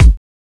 Wu-RZA-Kick 46.wav